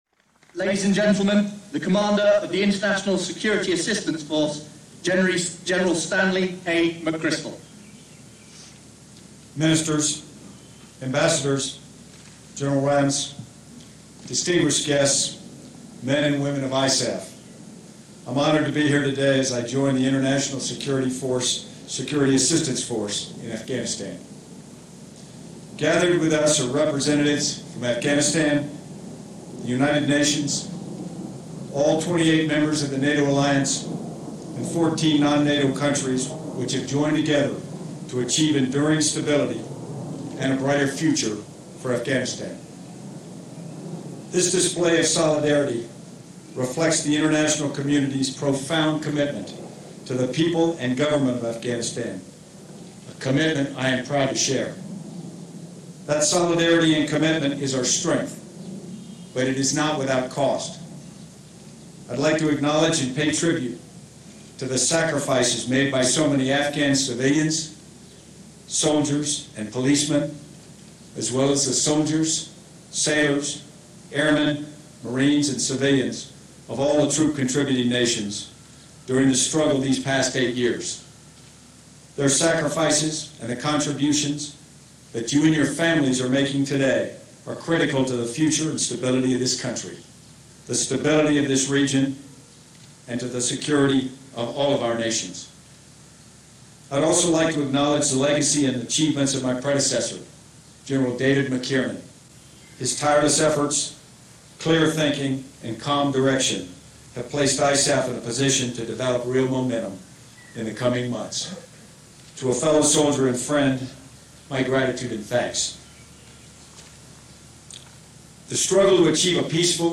Remarks at the International Security Assistance Force Change of Command Ceremony
delivered 15 June 2009, Kabul, Afghanistan
stanleymcchrystalISAFchangeofcommand.mp3